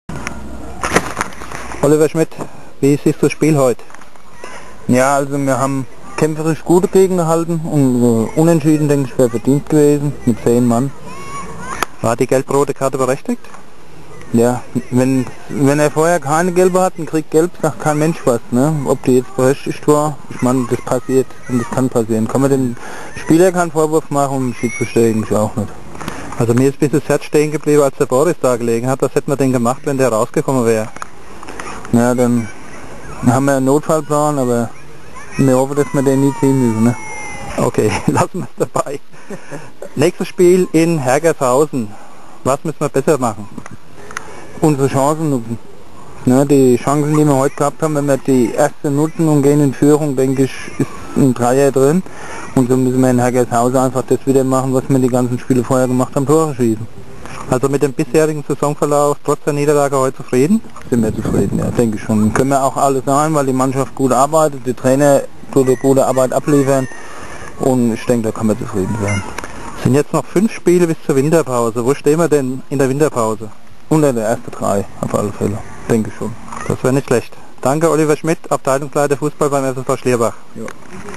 Nach dem Spitzenspiel gegen den Tabellenführer aus Lengfeld stand er am Grill.
Den kompletten Tonmitschnitt gibt's am kommenden Dienstag in "Nachgehakt".